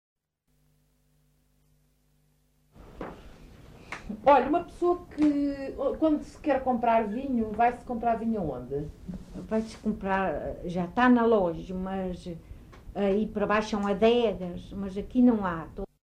Text view Fajãzinha, excerto 54 Localidade Fajãzinha (Lajes das Flores, Horta) Assunto Não aplicável Informante(s